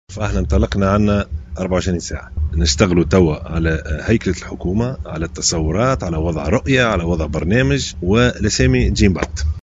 أكد رئيس حكومة الوحدة الوطنية المكلف يوسف الشاهد، في تصريح إعلامي مقتضب، اليوم الجمعة، أن مشاوراته بخصوص الحكومة الجديدة التي انطلقت منذ حوالي 24 ساعة، قد خصصت للتداول حول التركيبة، مضيفا أن الأسماء المقترحة لمسك حقائب وزارية ستطرح في مرحلة لاحقة.